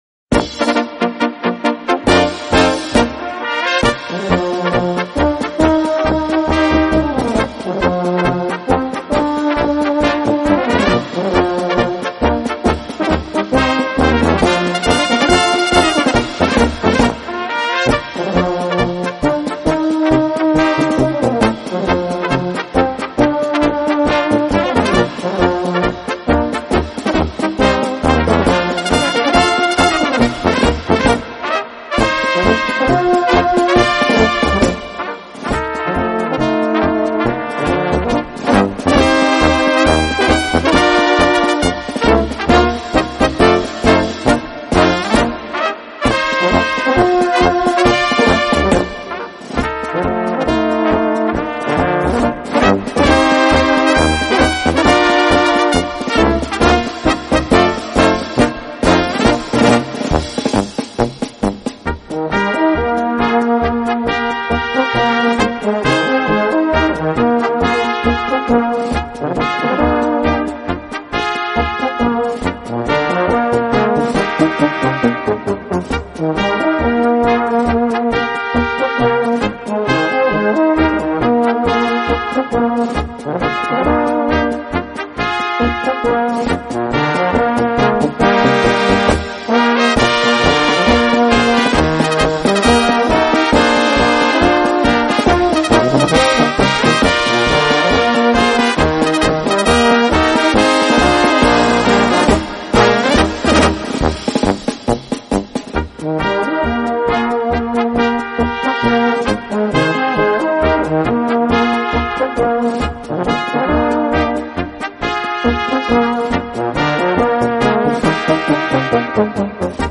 Gattung: Marsch für kleine Besetzung
Besetzung: Kleine Blasmusik-Besetzung
für böhmische Besetzung